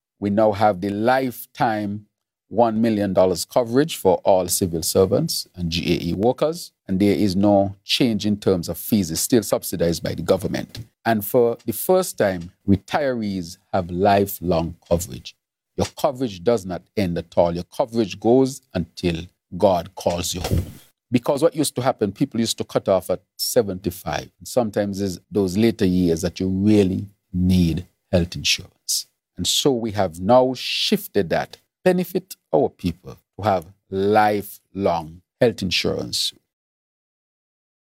PM Drew elaborated further about the expanded coverage, which took effect on Dec. 1st 2025.